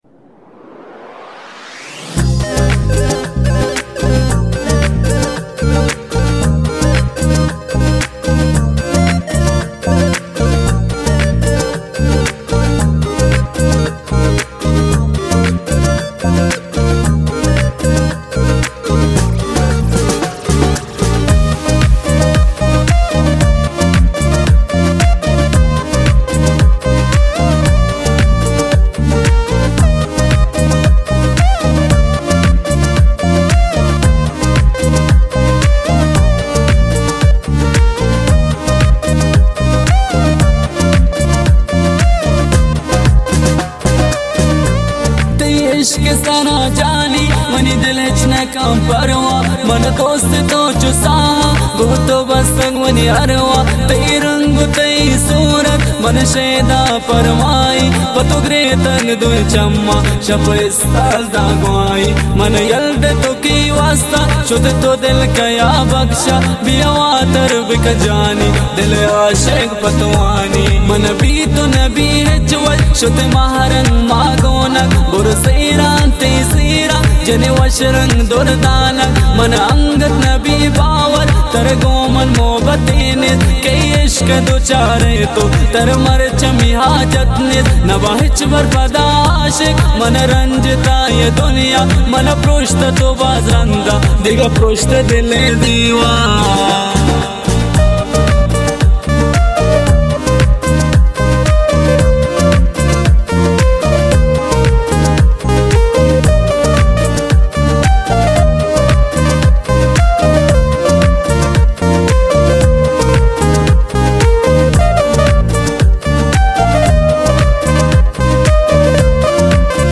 موسیقی بلوچی جدید